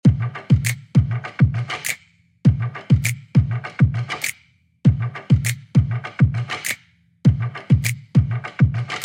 JP_CP_drum_loop_stick_beat_full_simple_100
hwasa-snap.mp3